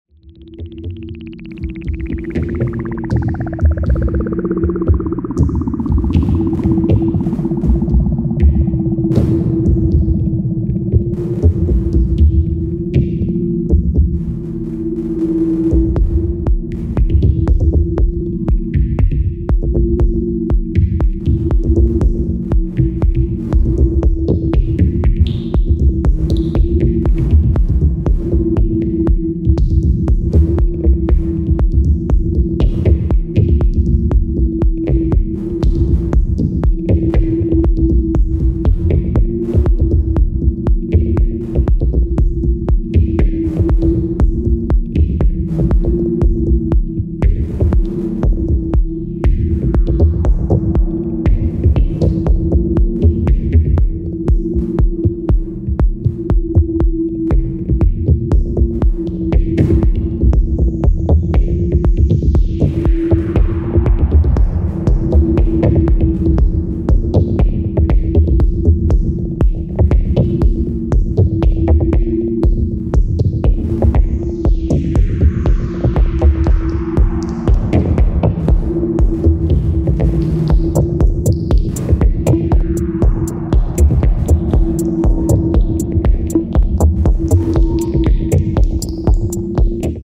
Lovely deep introverted techno tracks!
Electronix Techno Ambient